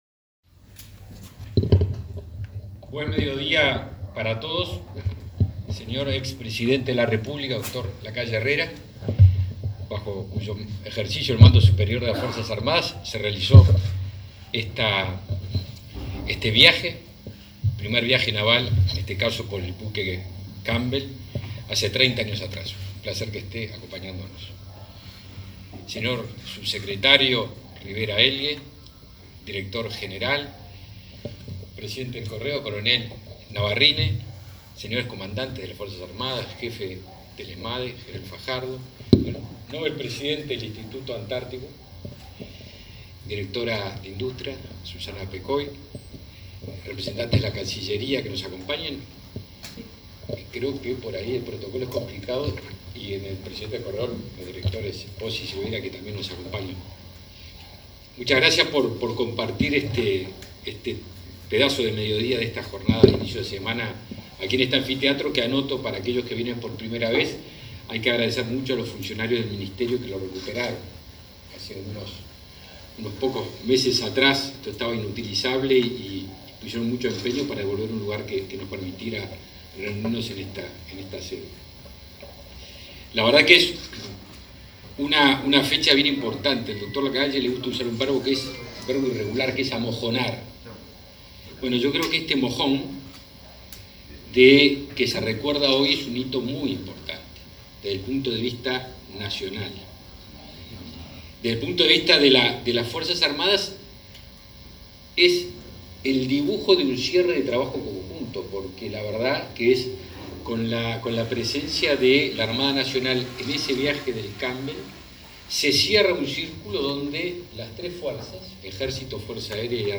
Declaraciones del ministro de Defensa Nacional, Javier García
En el evento, García se expresó en conferencia de prensa.